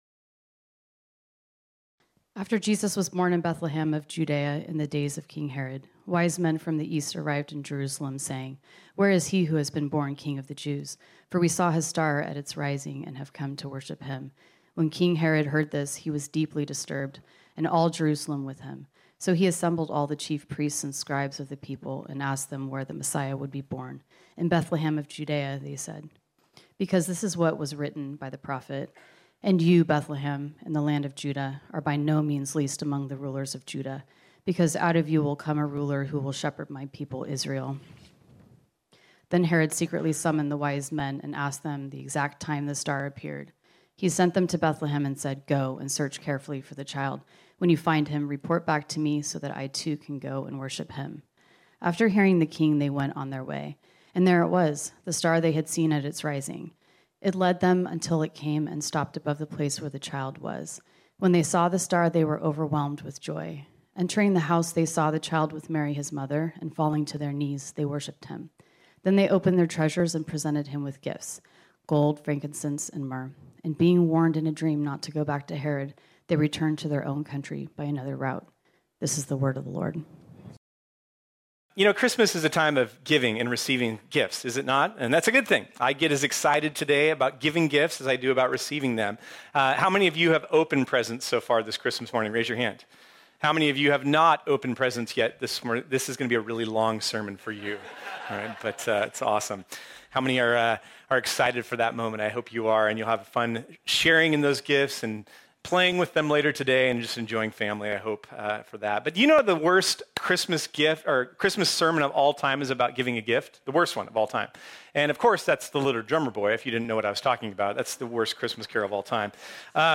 This sermon was originally preached on Sunday, December 25, 2022 .